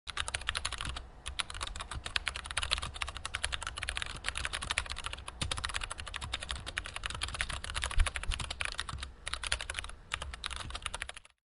Key Strokes
Key Strokes is a free foley sound effect available for download in MP3 format.
yt_aPpl8Oy-Iwc_key_strokes.mp3